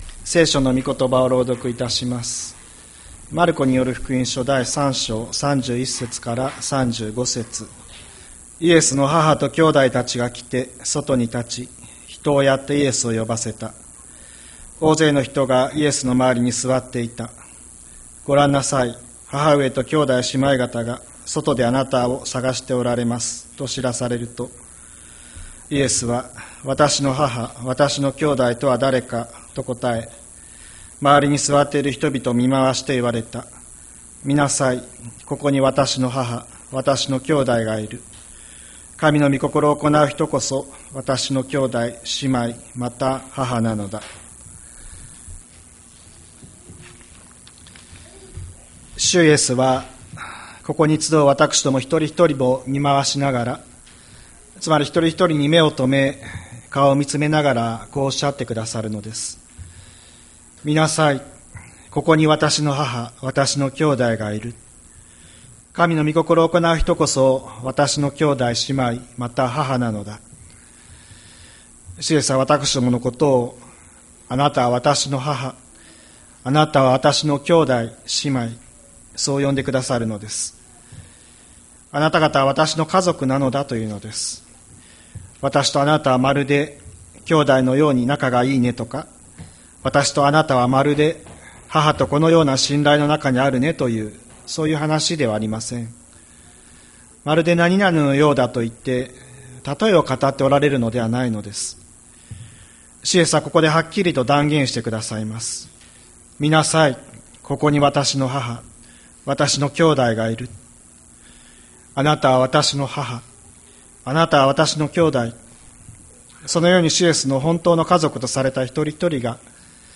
千里山教会 2025年07月06日の礼拝メッセージ。